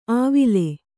♪ āvile